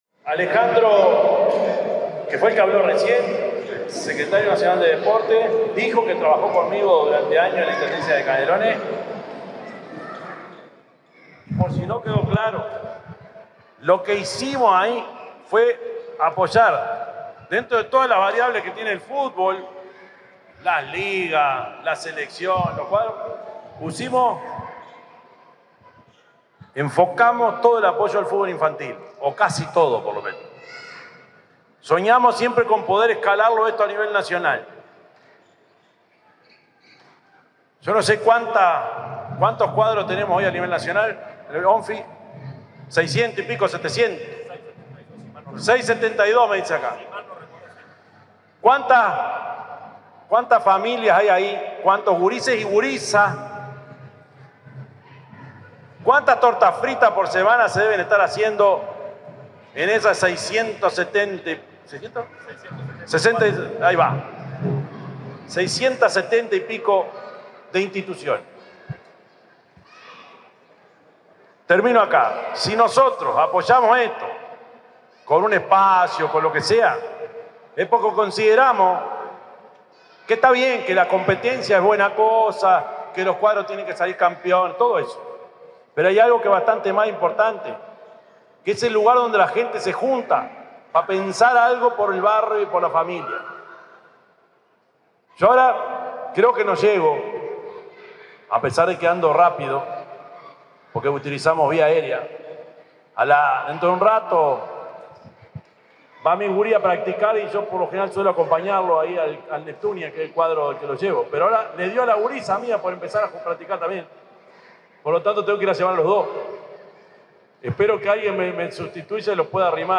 Palabras del presidente de la República,Yamandú Orsi
El presidente de la República, Yamandú Orsi, asistió al acto de entrega en comodato de un predio de la ANEP al club Salto Nuevo.